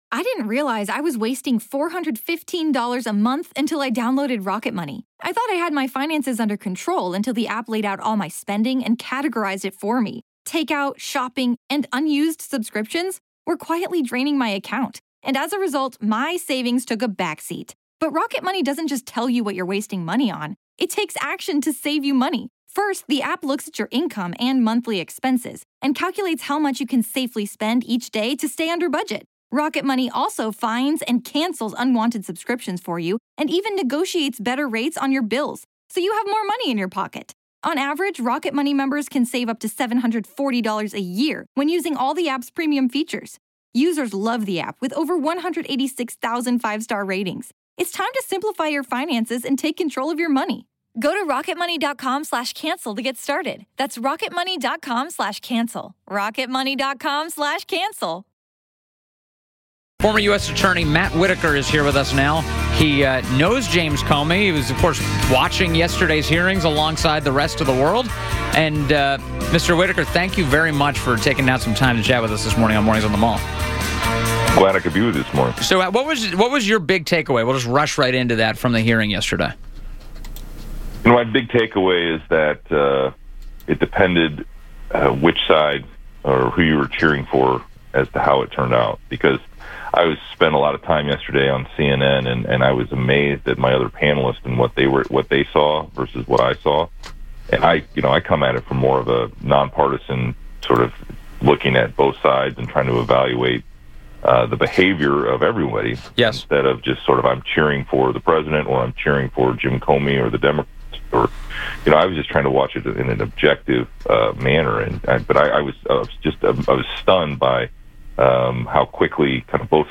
WMAL Interview - MATTHEW WHITAKER 06.09.17